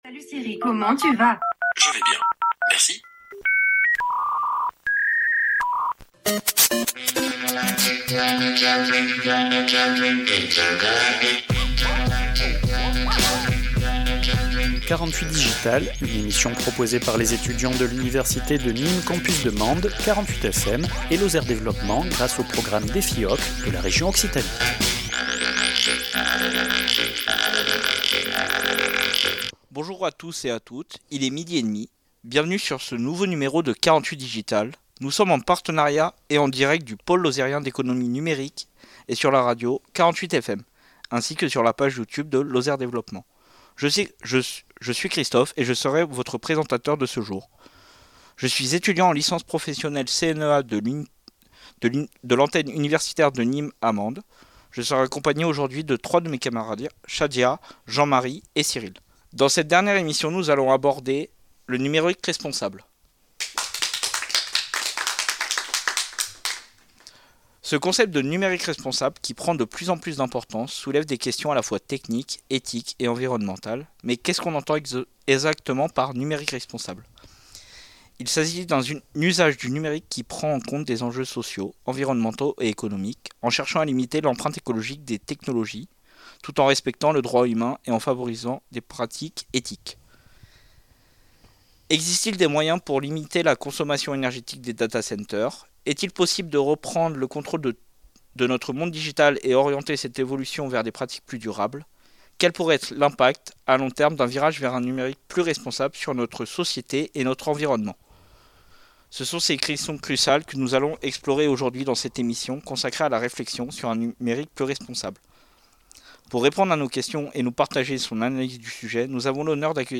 Une émission proposée par 48FM, l’Université de Nîmes antenne de Mende et Lozère développement